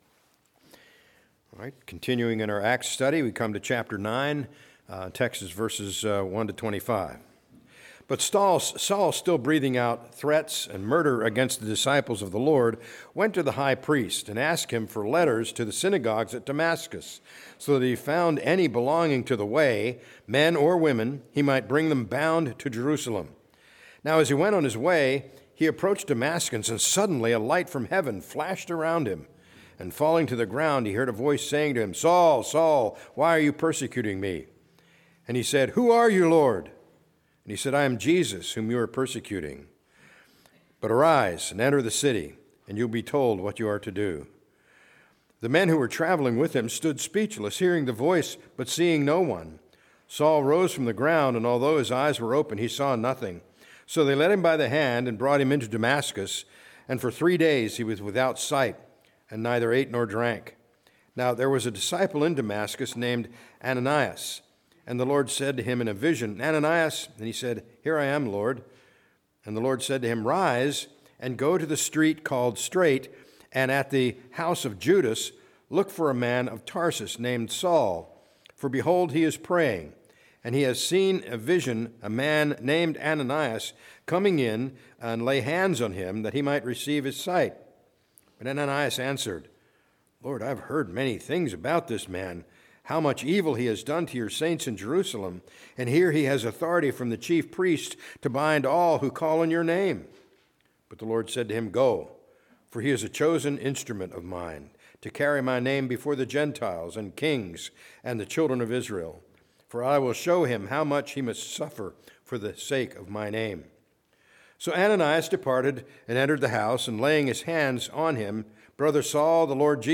A message from the series "Acts 2025."